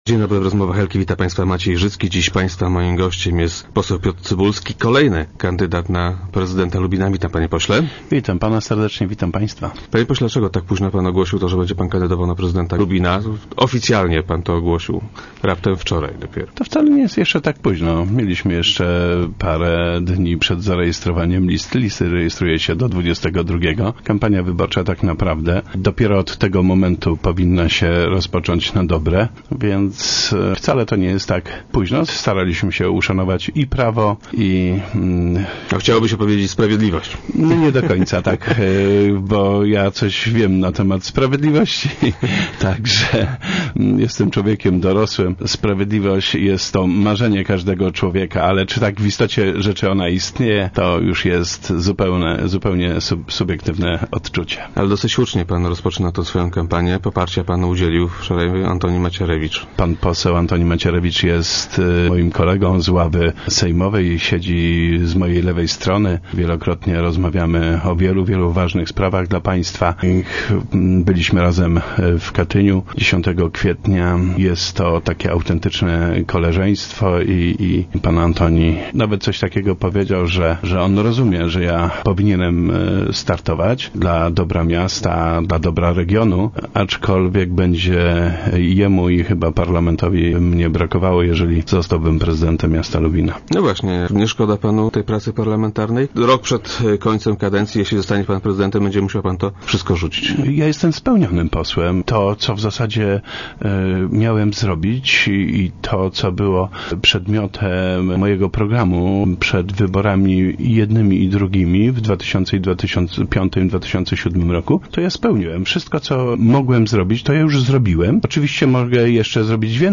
Kandydat był dziś gościem Rozmów Elki.